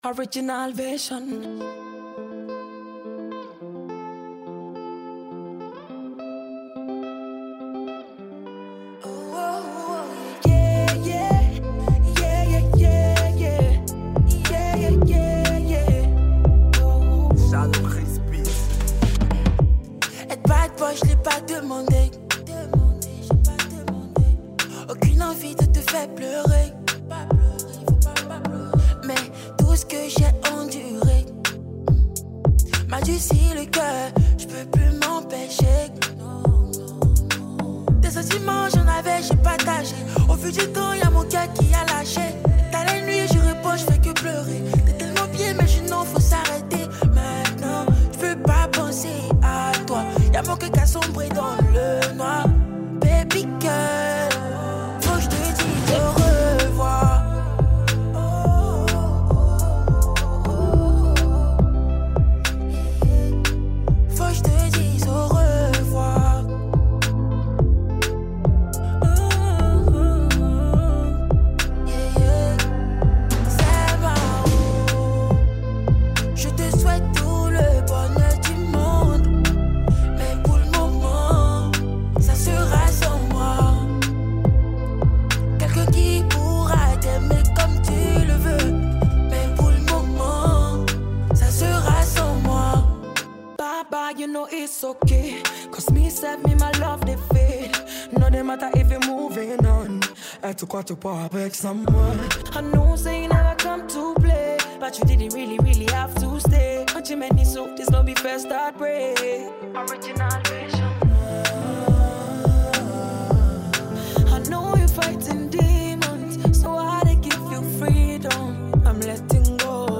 a duo from Ghana and the Ivory Coast.